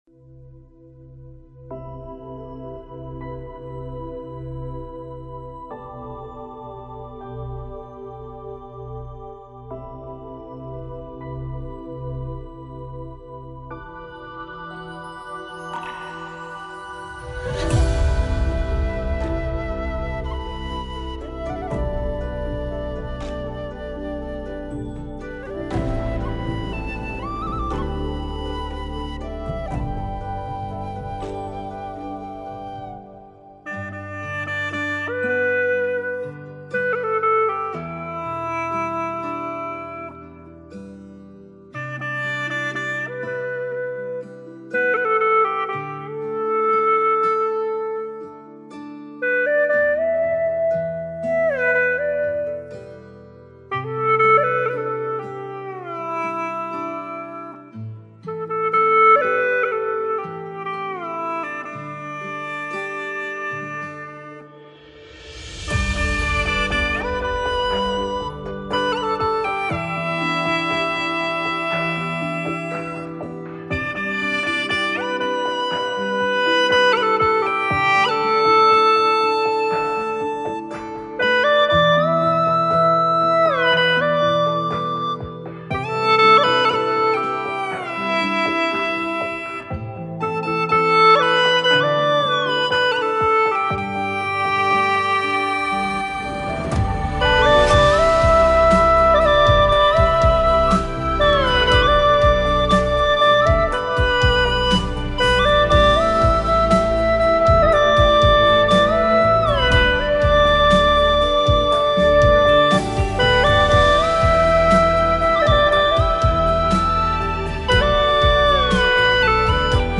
调式 : G
一首深情的情歌